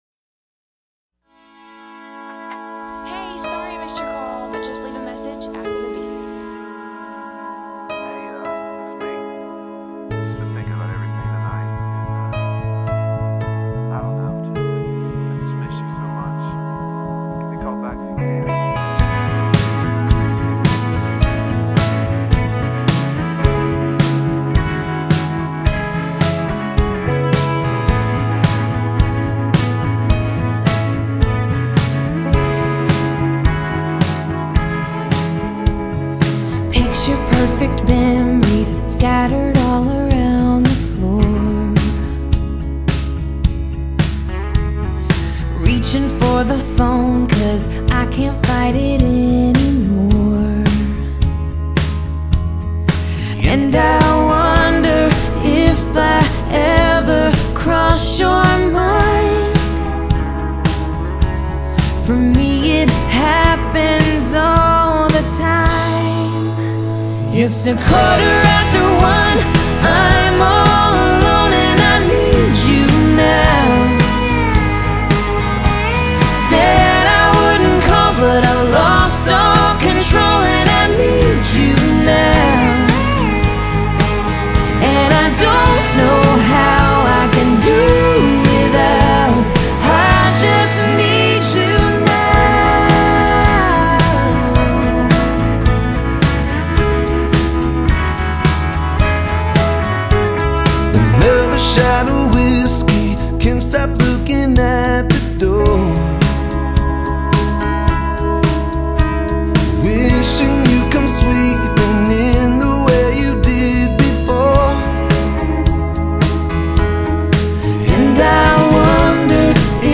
類型：Country, Country pop